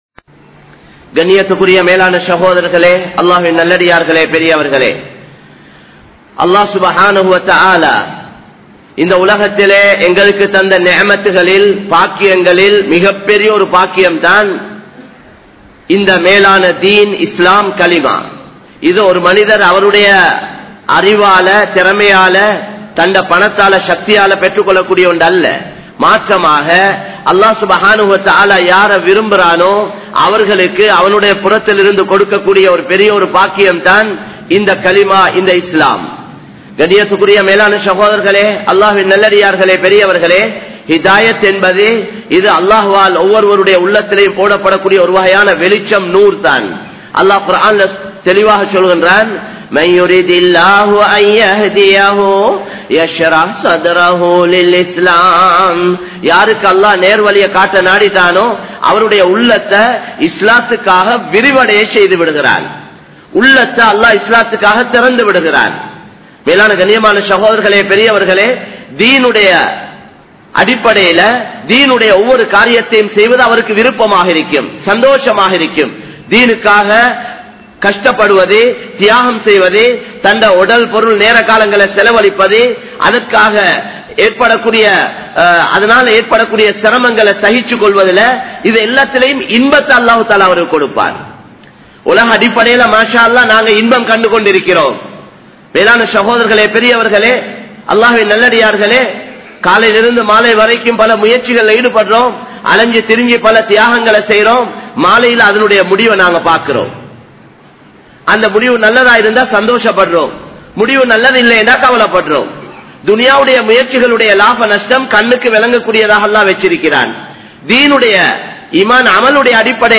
Intha Ummaththin Poruppu Enna? (இந்த உம்மத்தின் பொறுப்பு என்ன?) | Audio Bayans | All Ceylon Muslim Youth Community | Addalaichenai
Samman Kottu Jumua Masjith (Red Masjith)